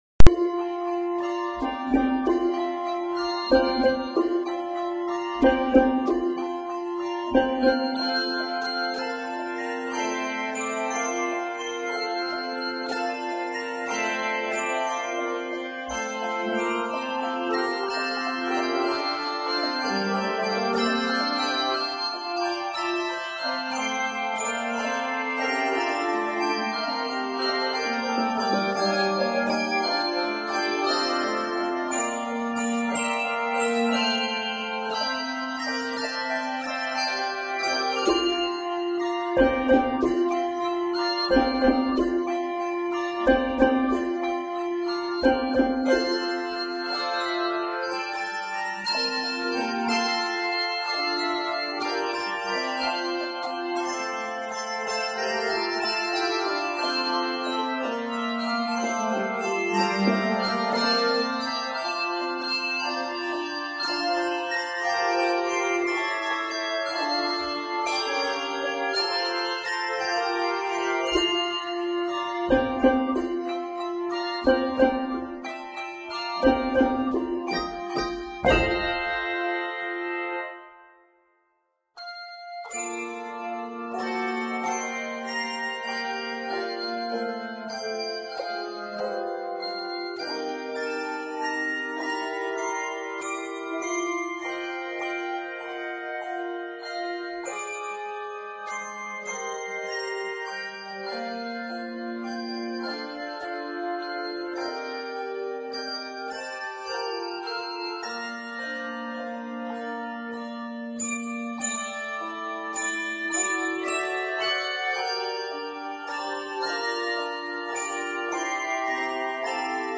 this bell piece